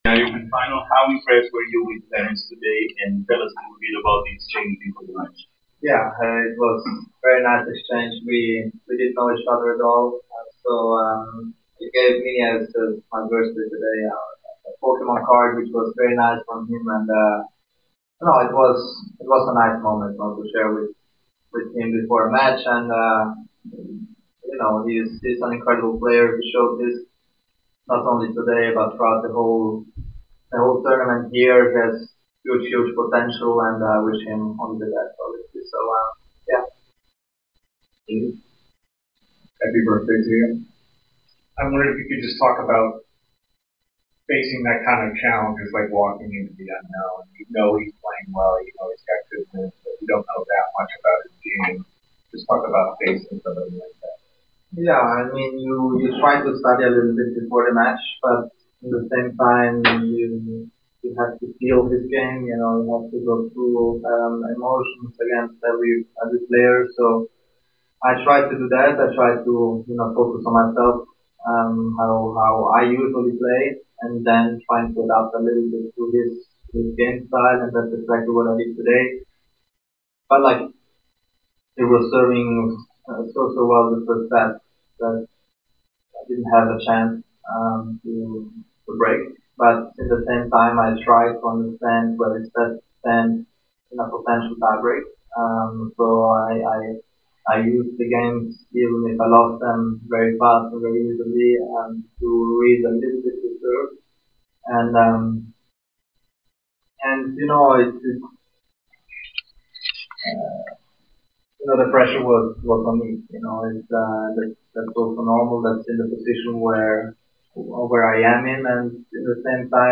Jannik Sinner post-match interview after defeating Terence Atmane 7-6, 6-2 in the Semifinals of the Cincinnati Open.